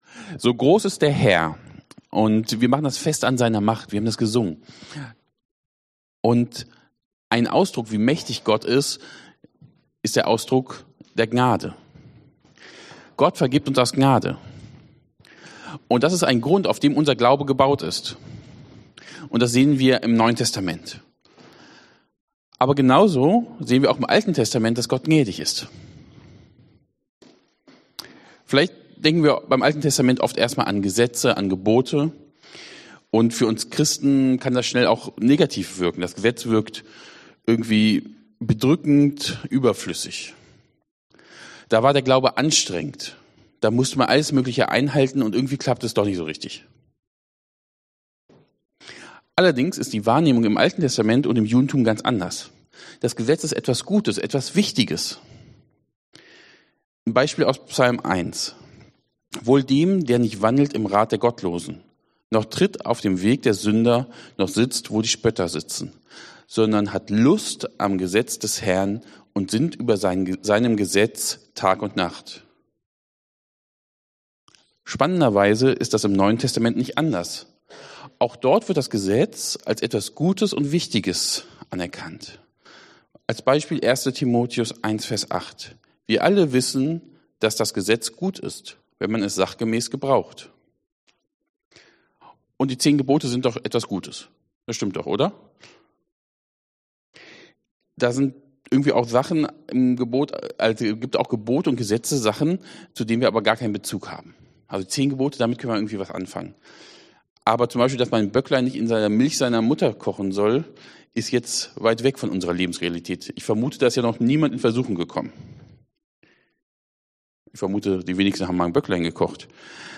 Hebräer Dienstart: Predigt Themen